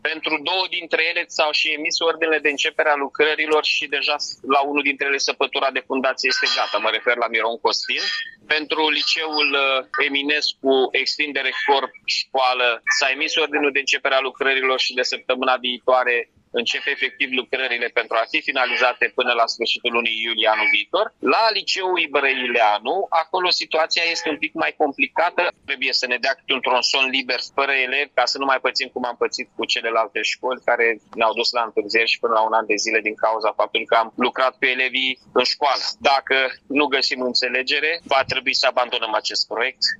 Edilul Mihai Chirica a declarat că același demers va fi făcut și la Colegiul Național „Garabet Ibrăileanu”, atunci când va fi eliberat corpul de clădire unde ar urma să fie efectuate lucrările.